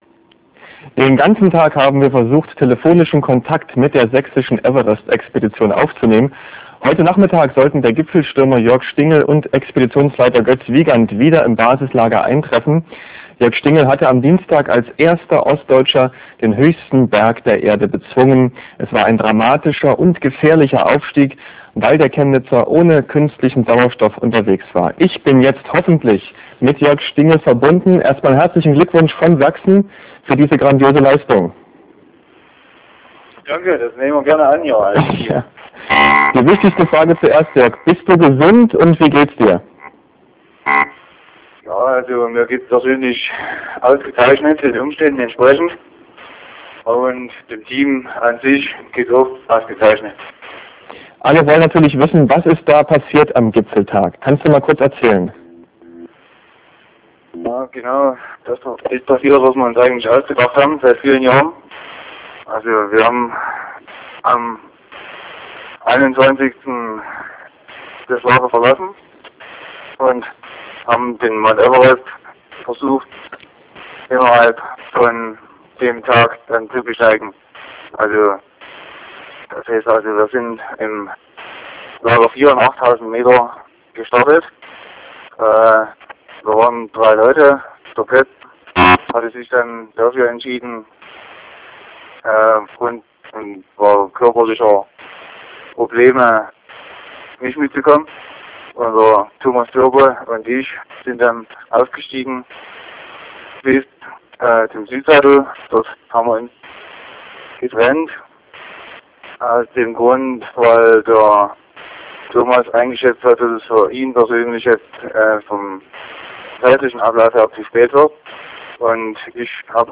Viertes Telefonat via Satellitentelefon ins